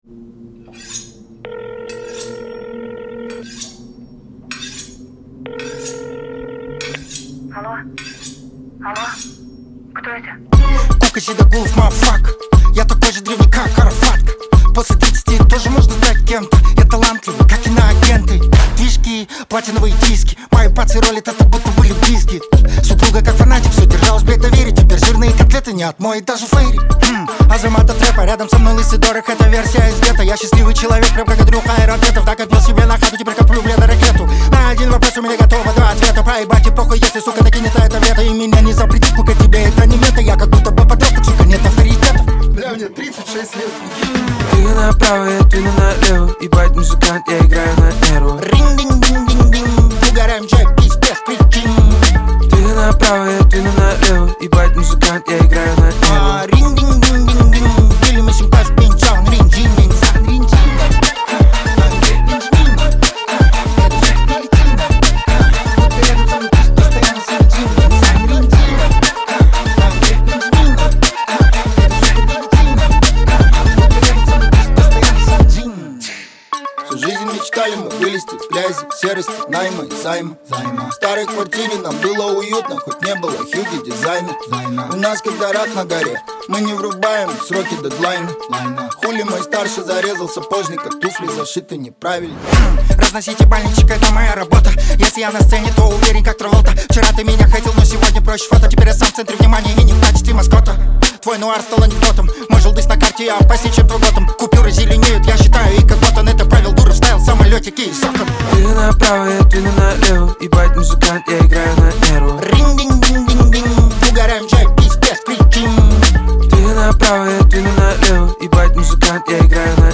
Трек размещён в разделе Рэп и хип-хоп / Русские песни.